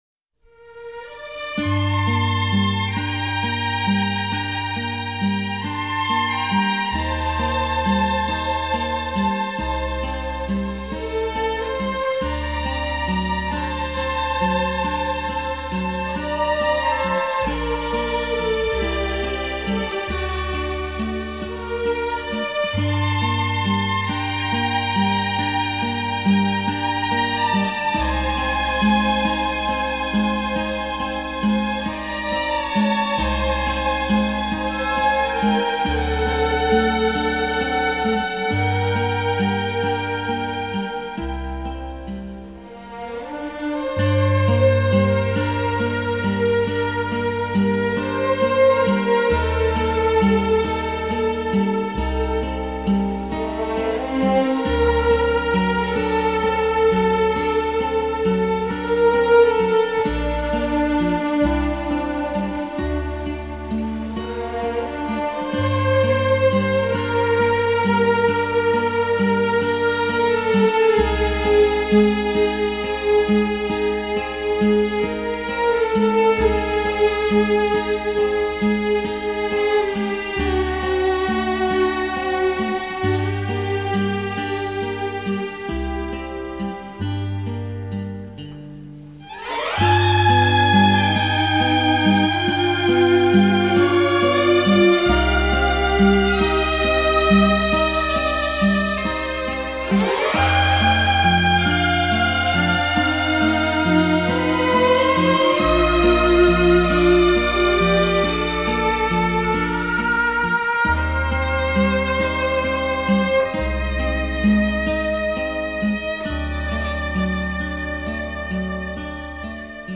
soundtrack
музыка кино